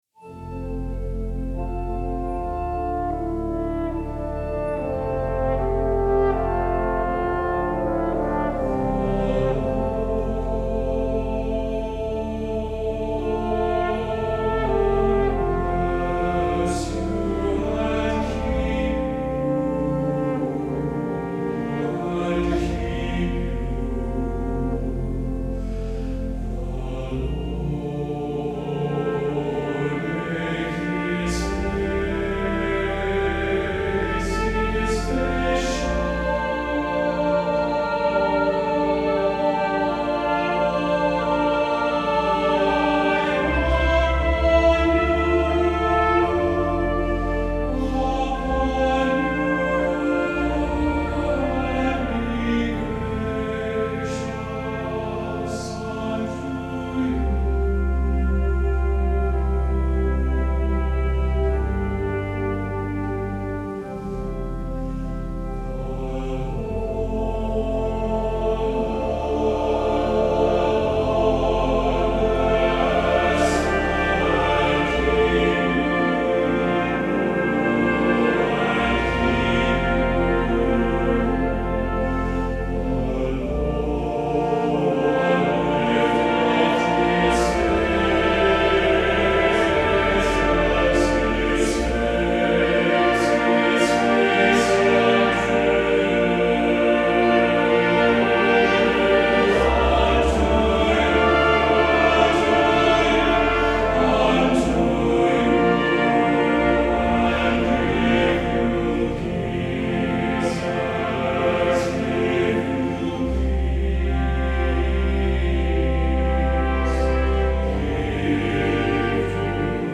Voicing: TTBB Brass Sextet